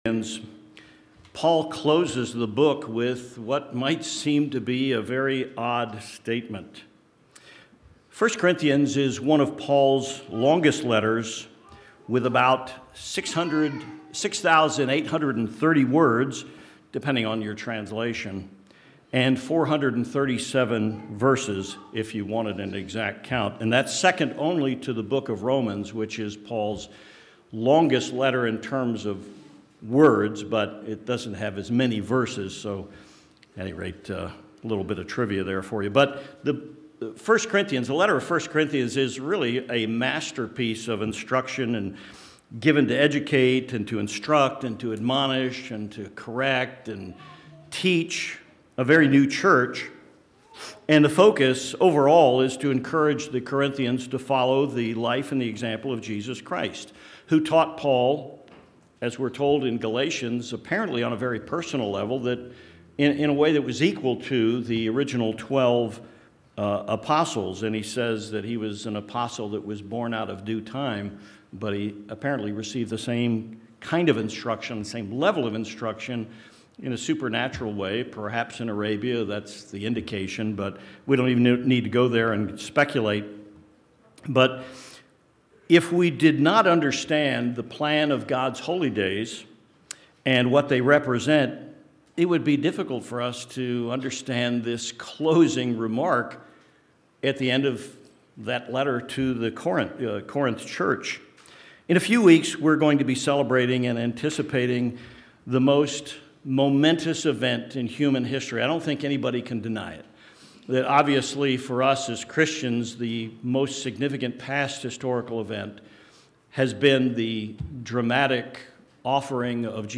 Sermons
Given in El Paso, TX Tucson, AZ